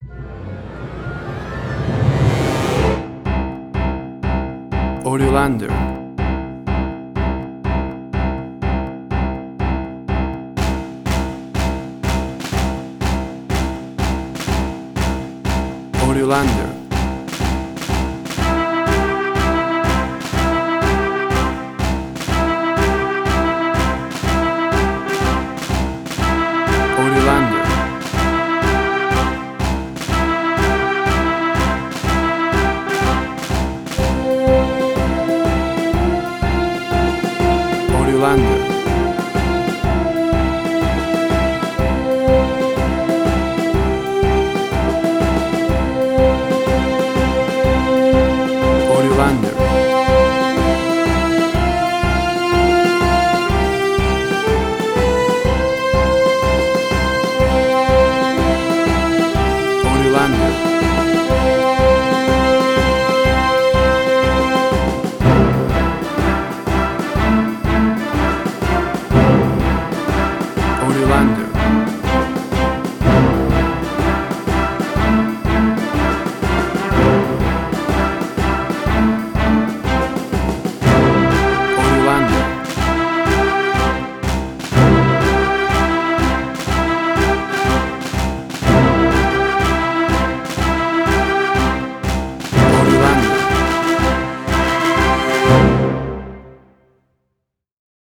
Spaghetti Western
Tempo (BPM): 123